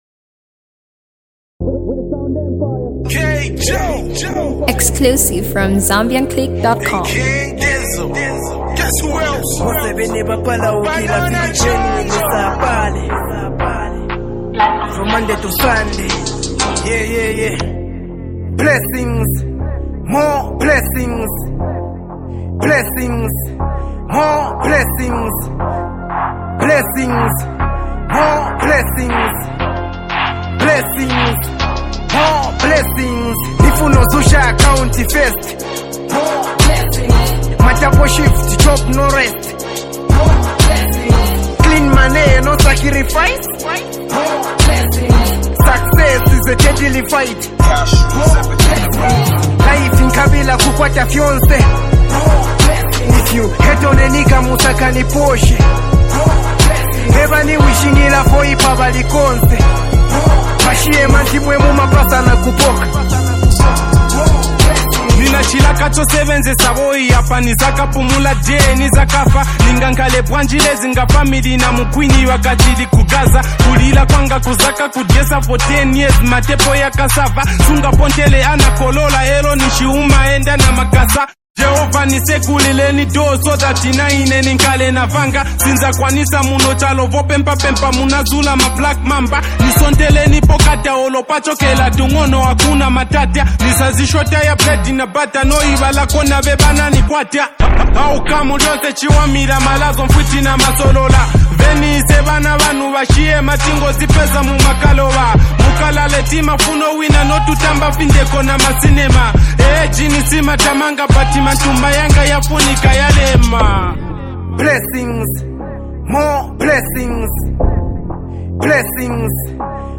the Zambian talented songwriter and award-winning rapper